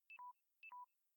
LowBattery.ogg